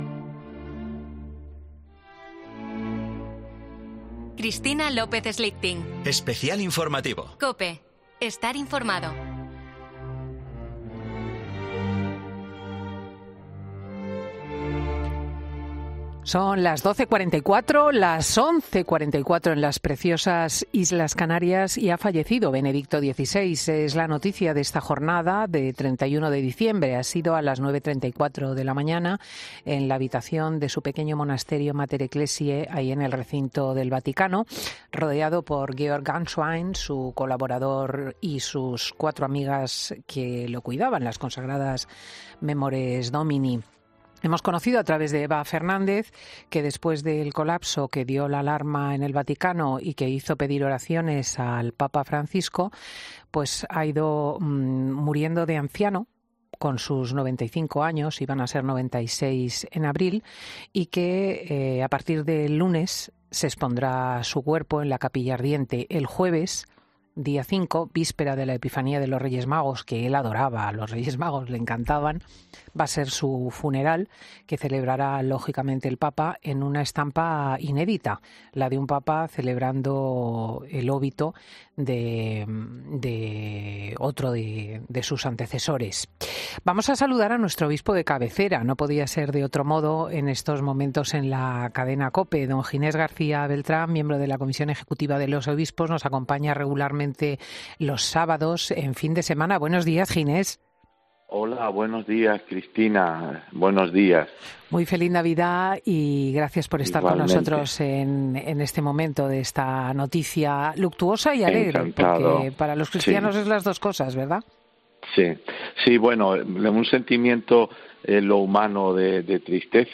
Con estas palabras se ha expresado el obispo de Getafe, Ginés García Beltrán , en una entrevista realizada por Cristina López Schlichting en Fin de Semana COPE.